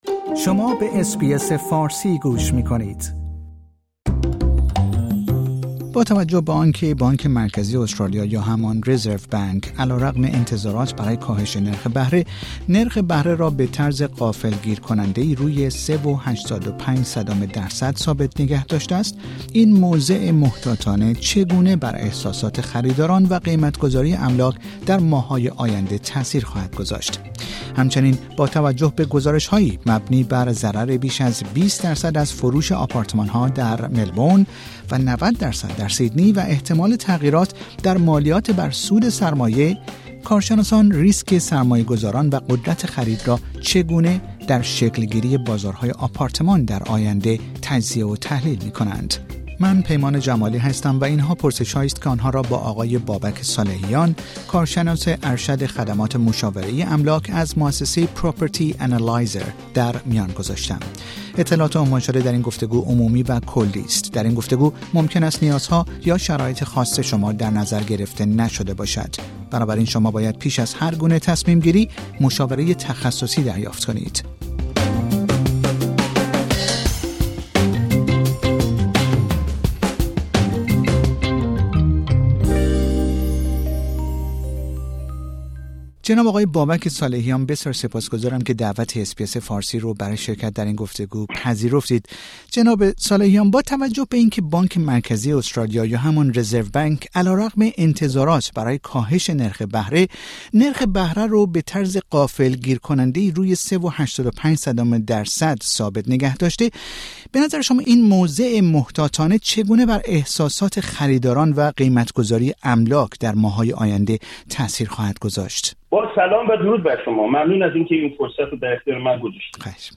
در گفتگویی